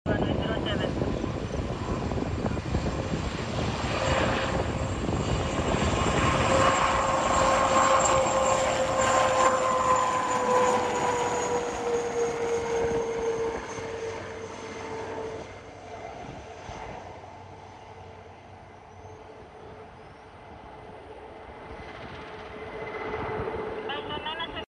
KAF 🎯 Eurocopter SA330 Super sound effects free download